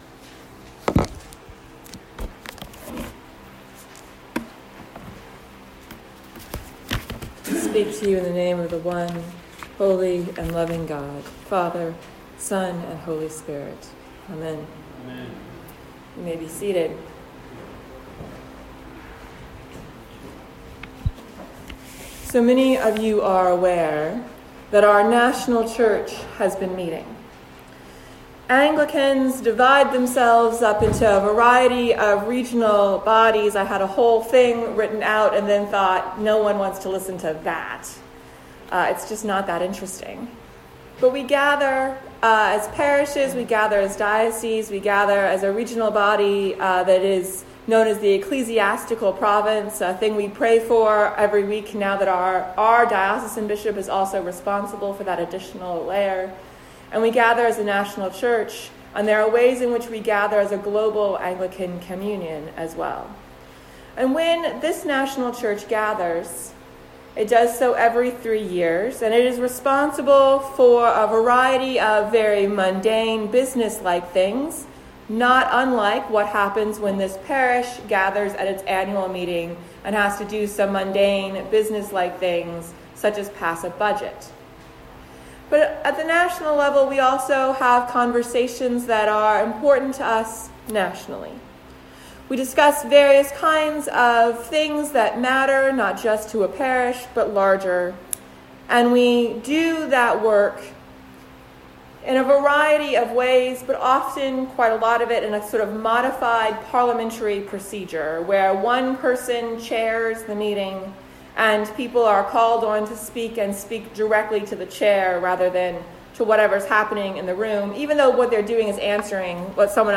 Sermons | St. Stephen the Martyr Anglican Church